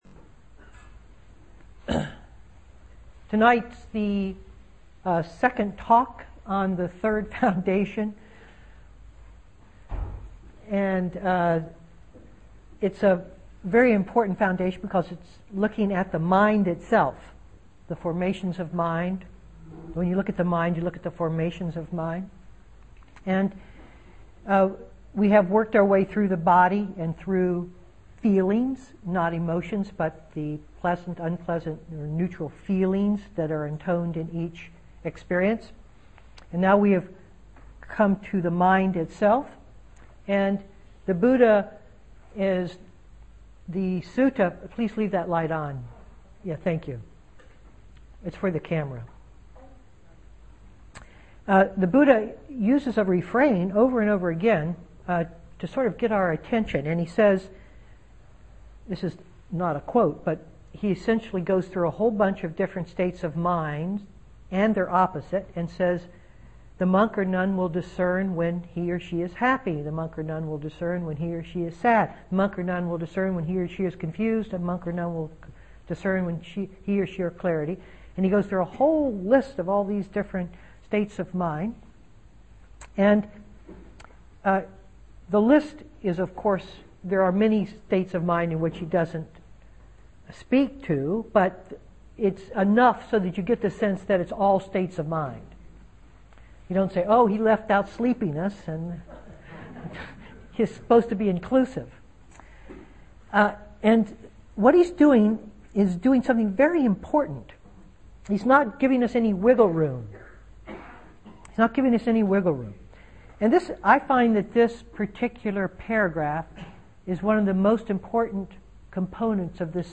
Venue: Seattle Insight Meditation Center